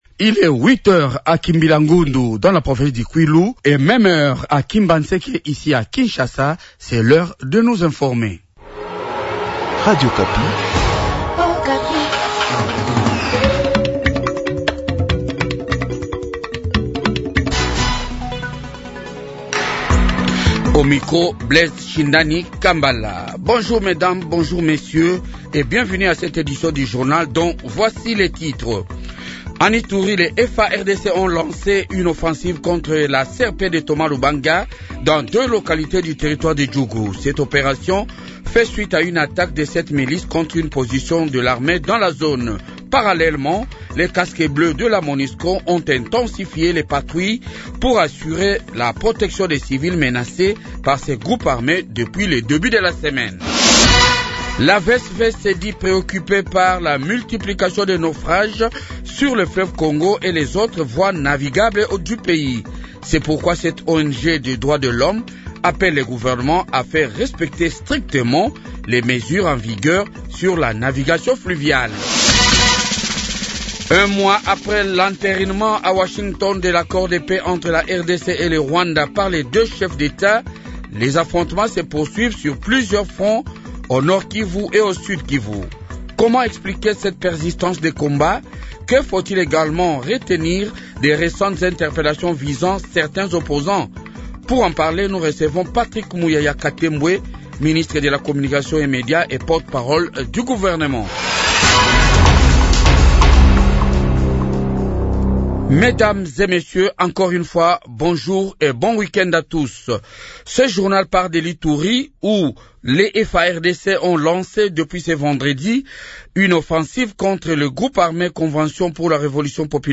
Invité: Patrick Muyaya, ministre de la Communication et des medias